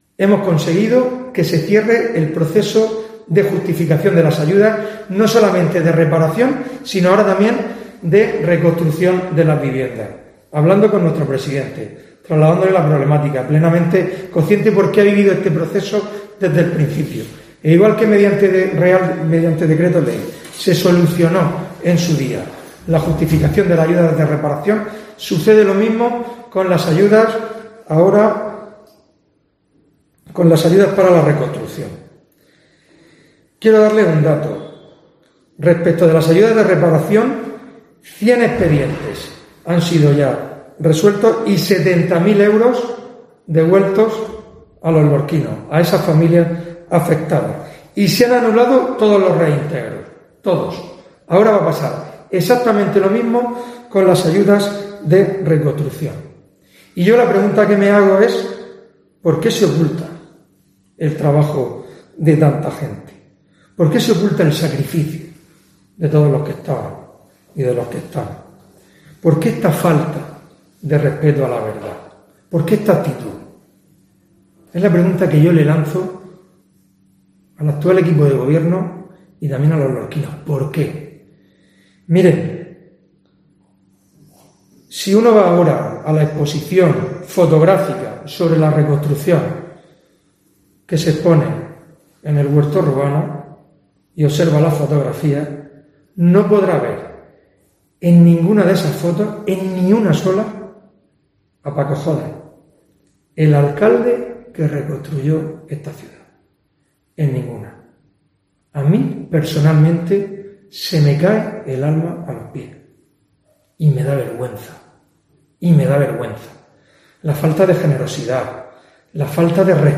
Fulgencio Gil, portavoz del PP